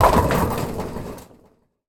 bowling_ball_pin_strike_01.wav